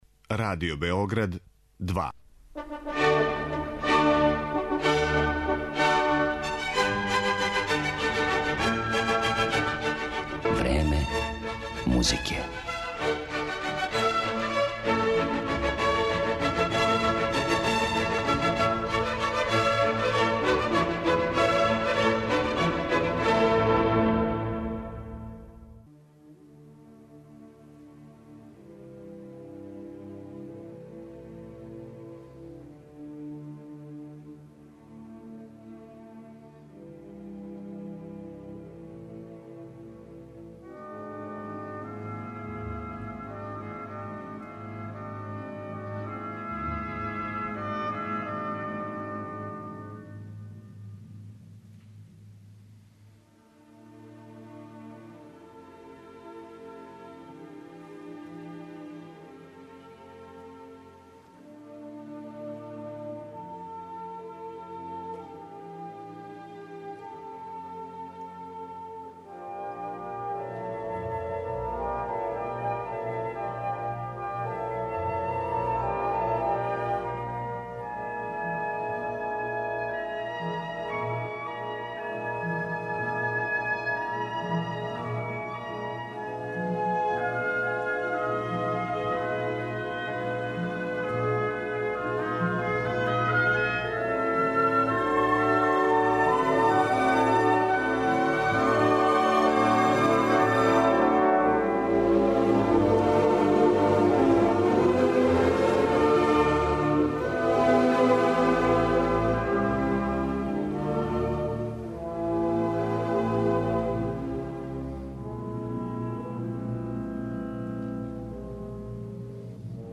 Данашња емисија Време музике доноси фрагменте из композиција ових аутора у извођењу врхунских оркестара и вокалних солиста.